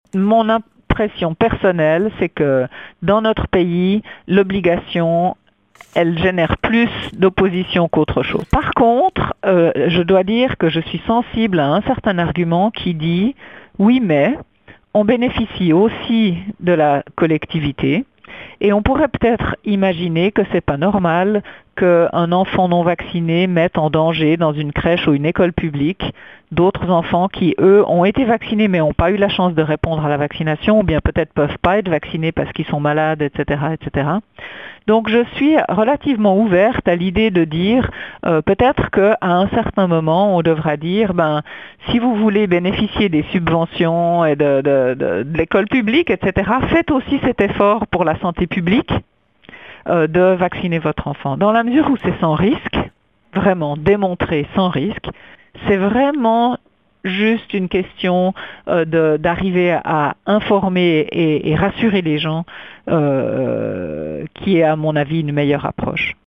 Claire-Anne Siegrist, présidente de la Commission fédérale pour les vaccinations.